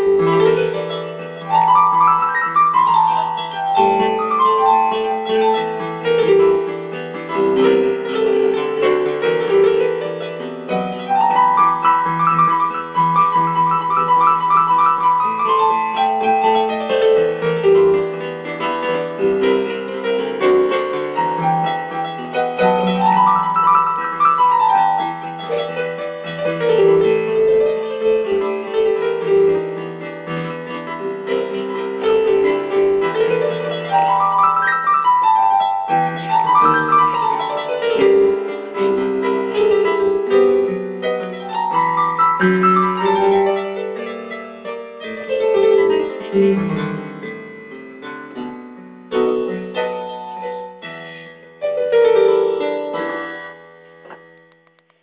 Импровизация на пиано.